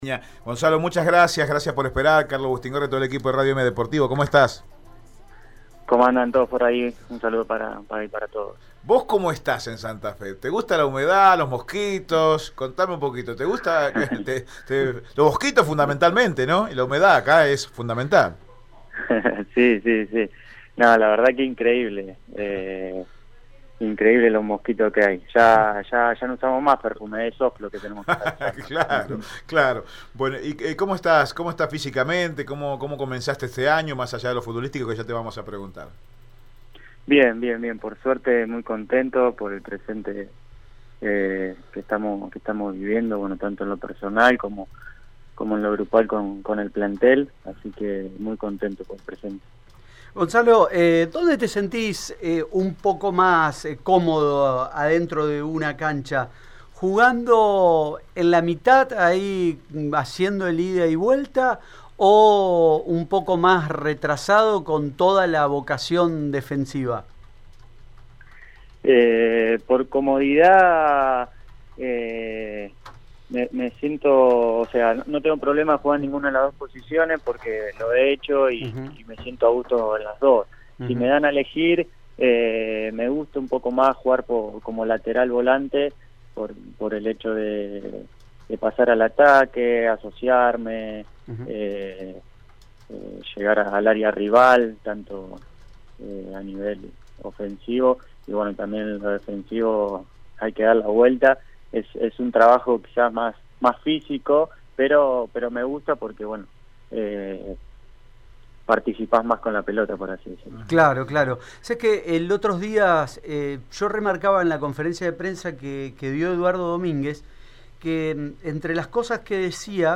El defensor Sabalero habló en Radio EME Deportivo sobre el presente del equipo, y palpitó el enfrentamiento contra Estudiantes en La Plata.